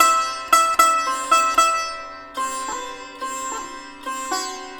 100-SITAR4-L.wav